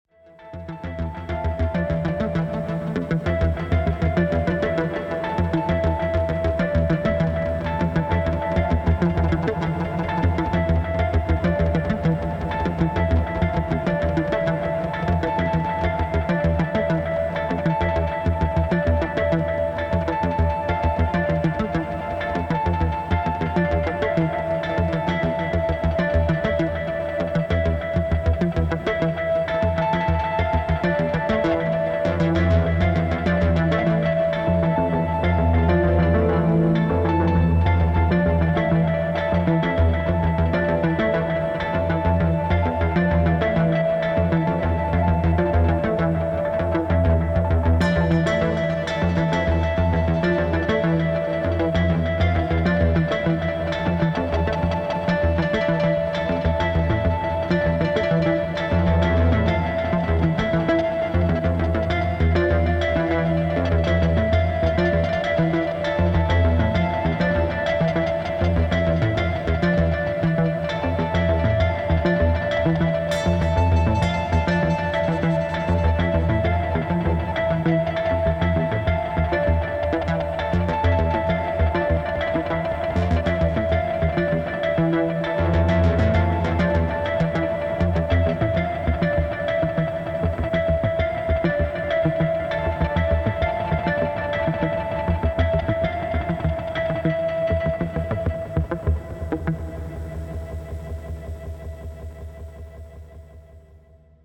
Microfreak, Digitone, pedals.